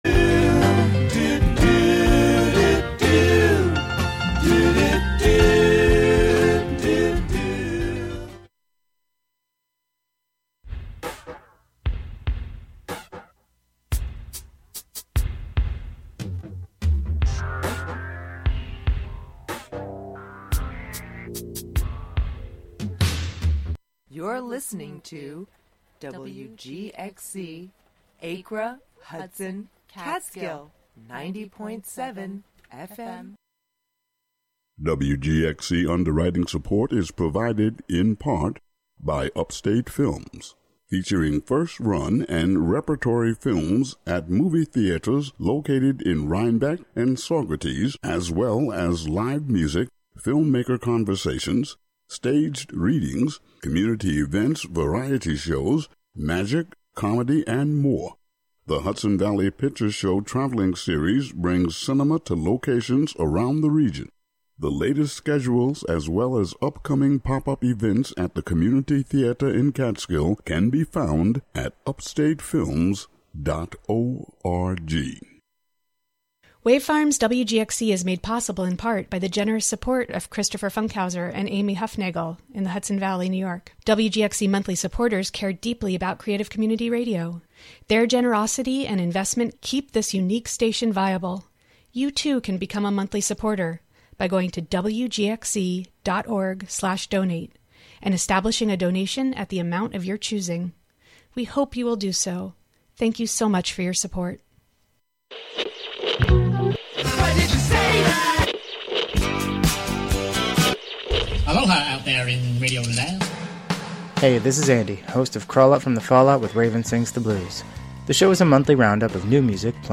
Monthly excursions into music, soundscape, audio document, and spoken word, inspired by the wide world of performance. This month we consider the Constitution. Live from Ulster County.